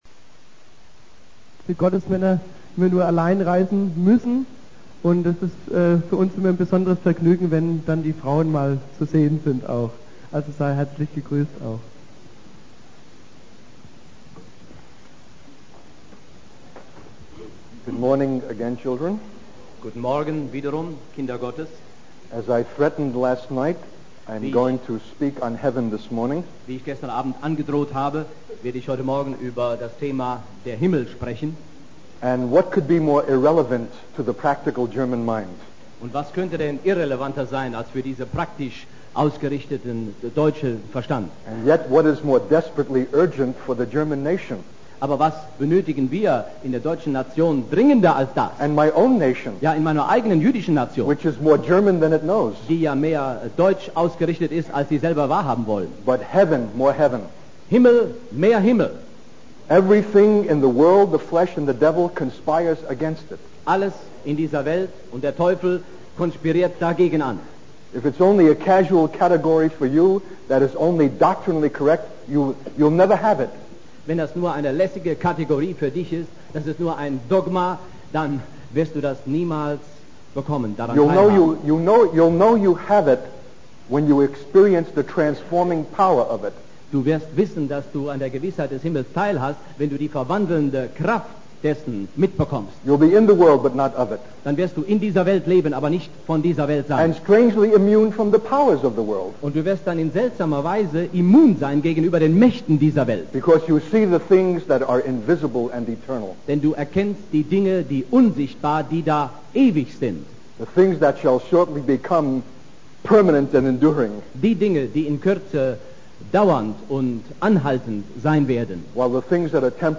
In this sermon, the speaker emphasizes the importance of setting our affections on heavenly things rather than earthly distractions. He shares a personal anecdote about wishing he had students who sought answers from above rather than giving predictable earthly answers. The speaker encourages the audience to shut off earthly distractions like the radio and TV and seek that which is above.